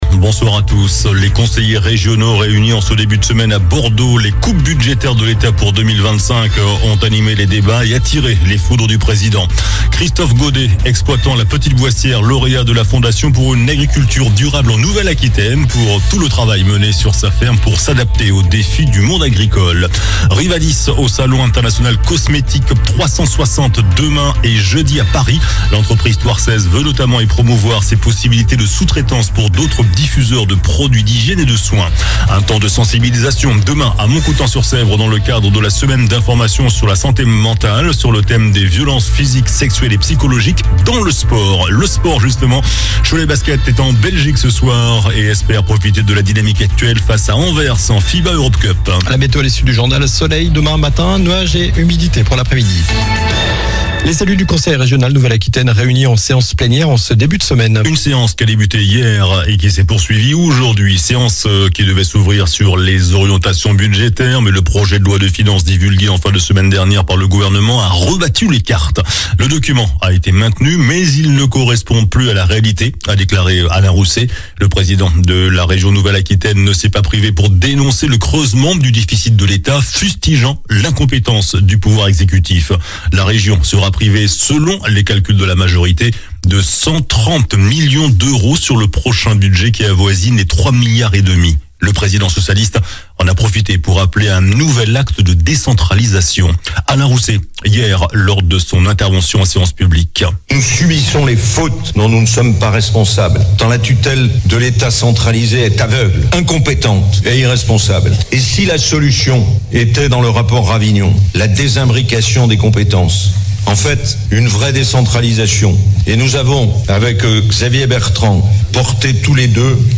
Journal du mardi 15 octobre (soir)